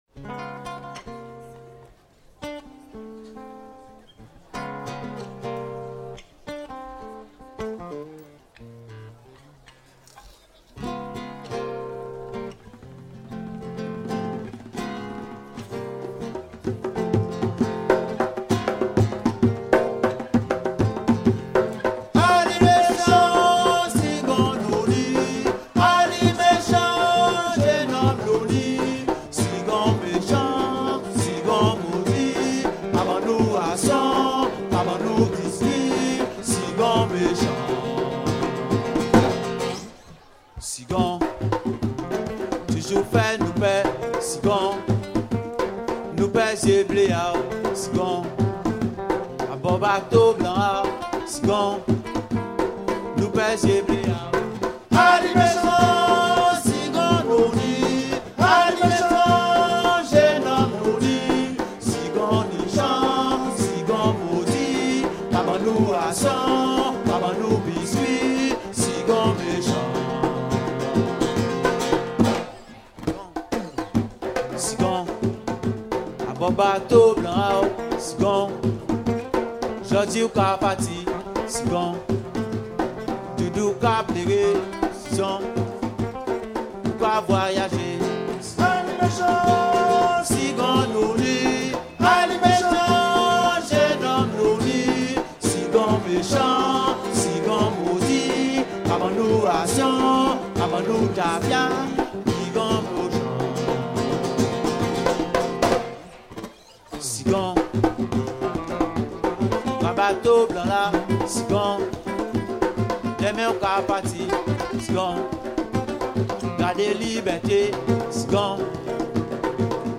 circonstance : maritimes
Genre strophique